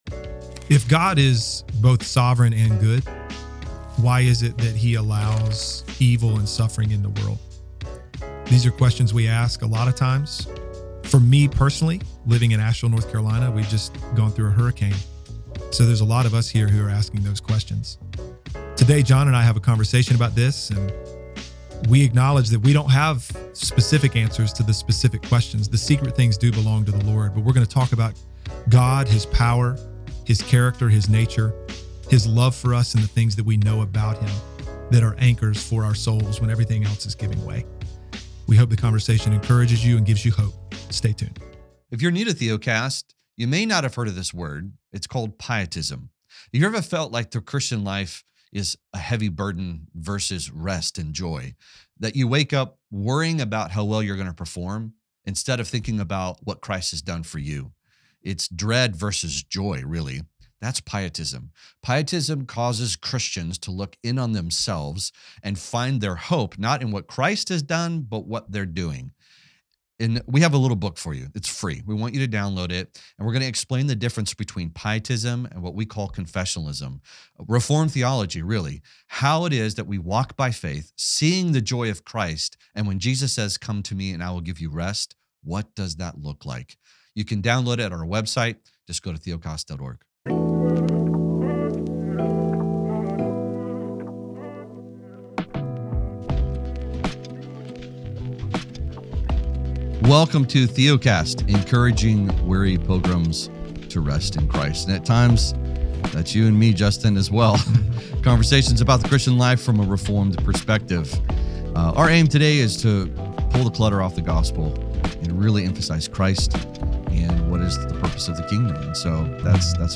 We hope this conversation encourages you and offers you hope.